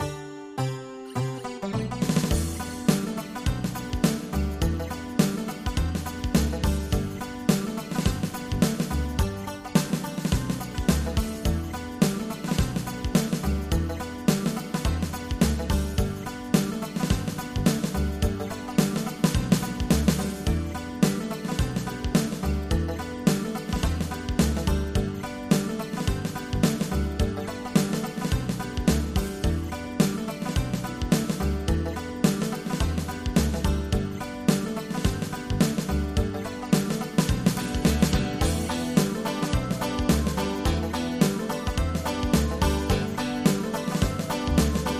MIDI · Karaoke
bateria